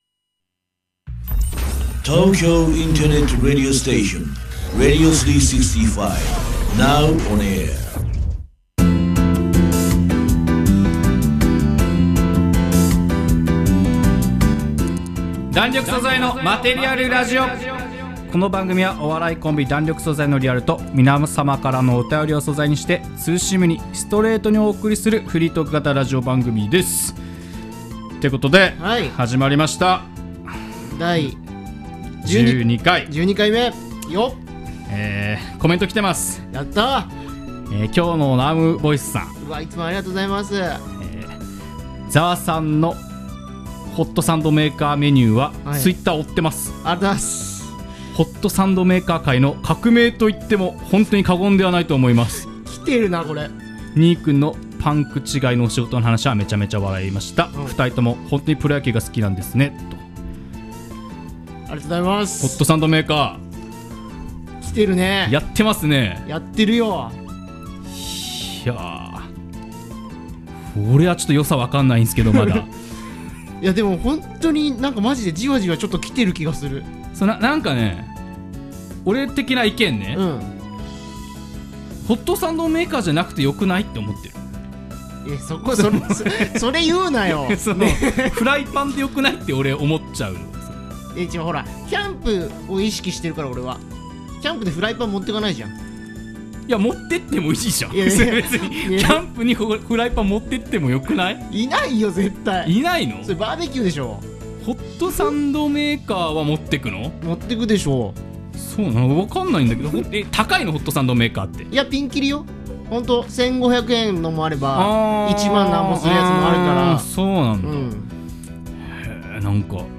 【注：収録時の機材トラブルの為、今回はエンディング無しでお届けします】